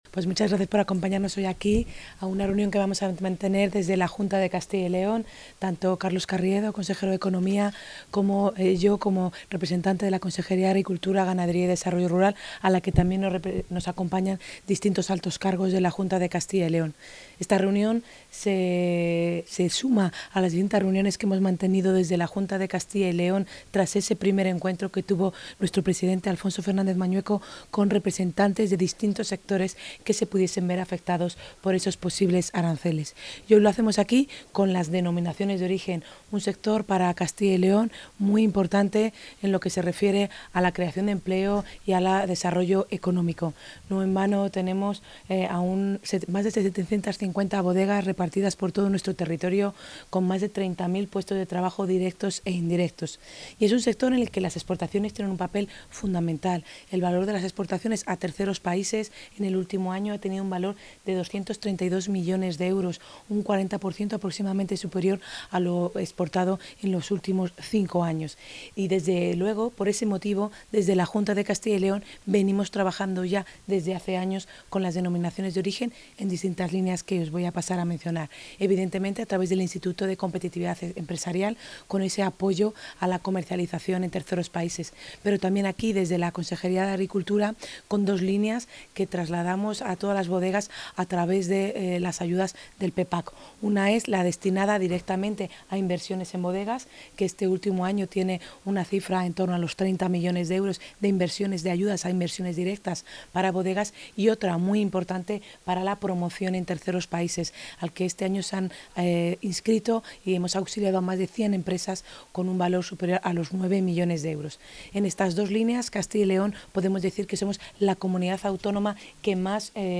Declaraciones de la consejera de Agricultura, Ganadería y DR. Abre una nueva ventana